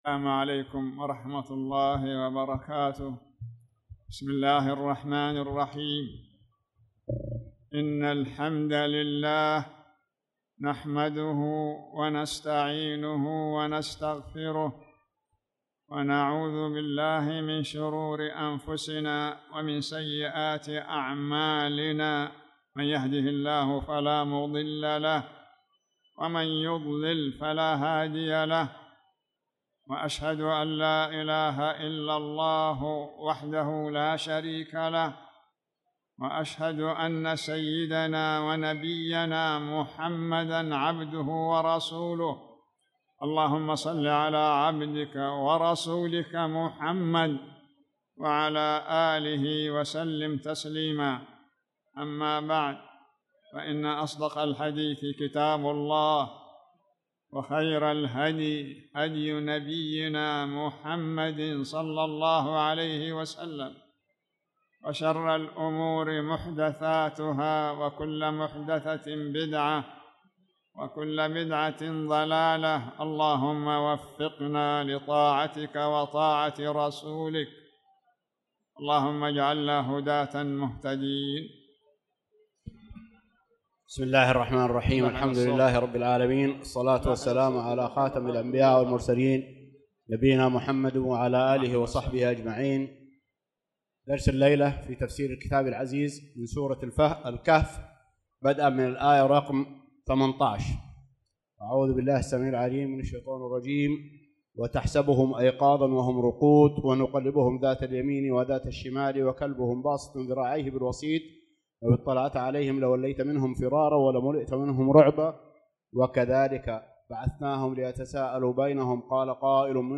تاريخ النشر ٢٧ شوال ١٤٣٧ هـ المكان: المسجد الحرام الشيخ